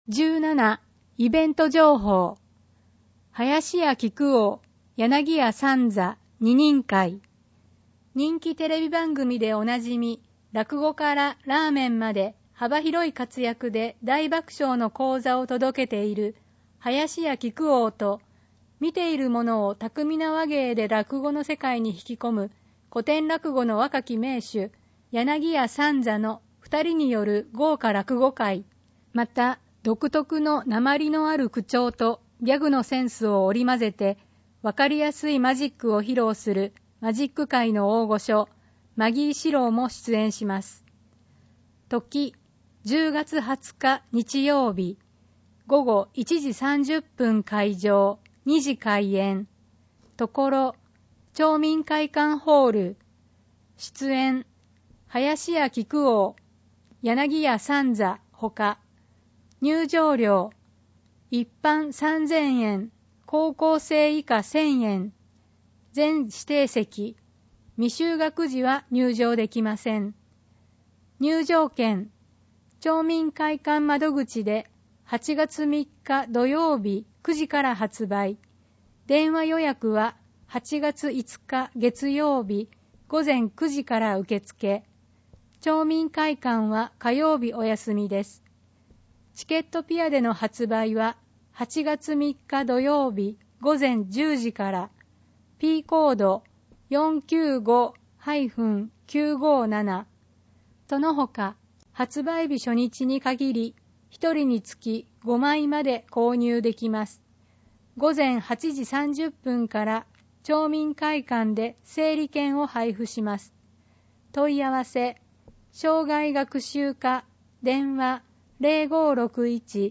広報とうごう音訳版（2019年8月号）